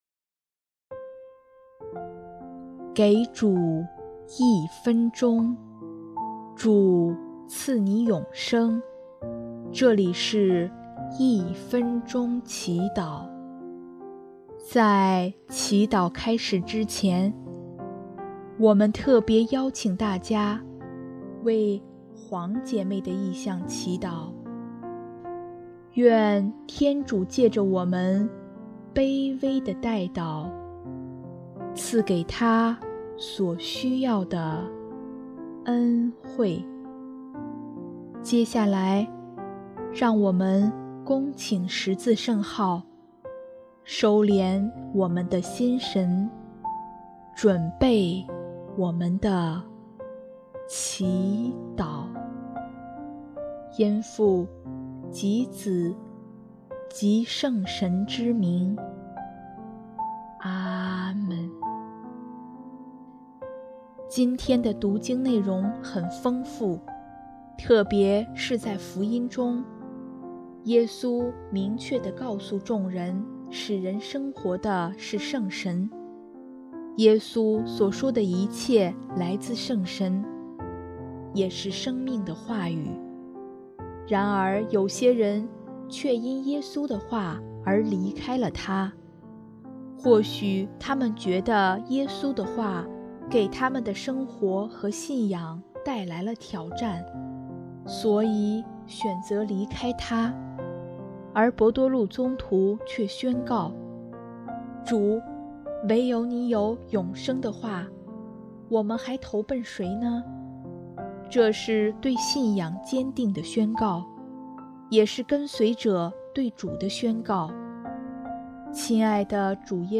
【一分钟祈祷】|8月25日 唯独祢有永生的话